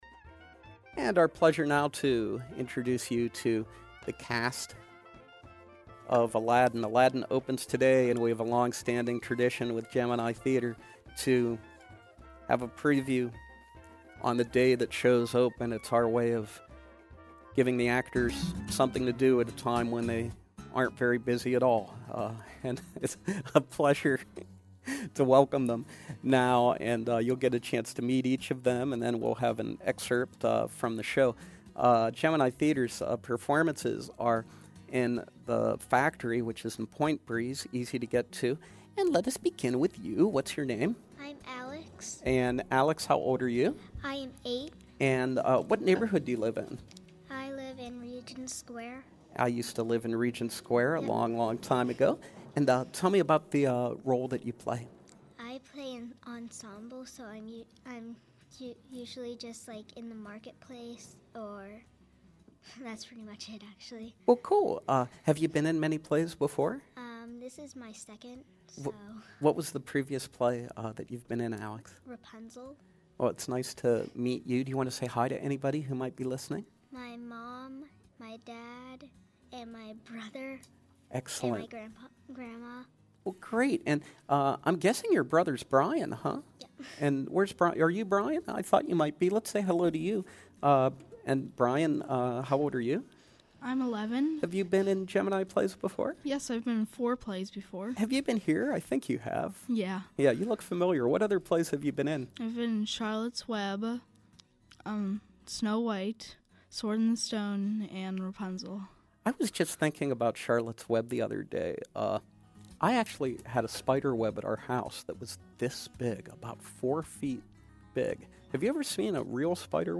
The cast of Aladdin, now at The Gemini Theater, are LIVE in the SLB studio for a sneak-peek of opening night and this season’s other upcoming productions.
Gemini Theater Interview